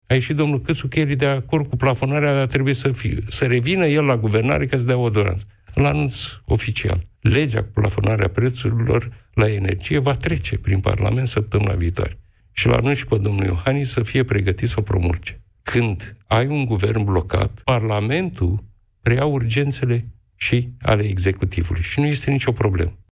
Legea privind plafonarea prețurilor la energie, depusă de PSD – va fi adoptată săptămâna viitoare, a anunțat liderul social-democrat – Marcel Ciolacu, în emisiunea Piața Victoriei.